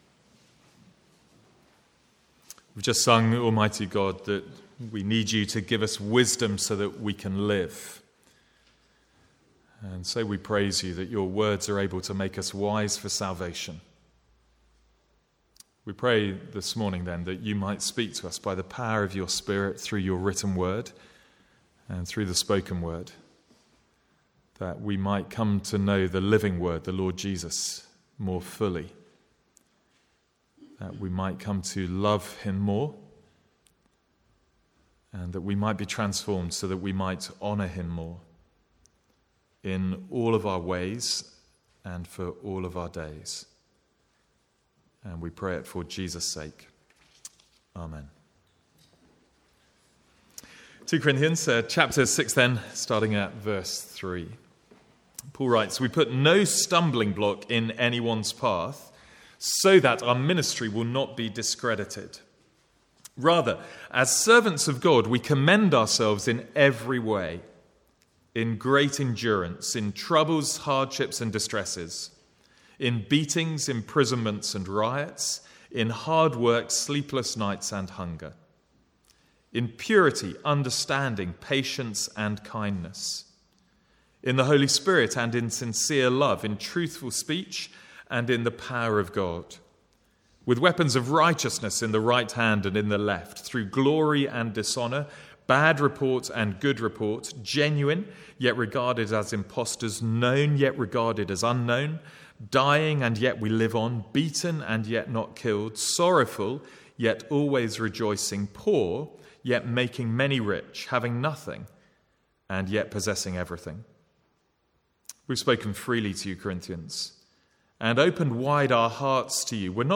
From the Sunday morning series in 2 Corinthians.
Sermon Notes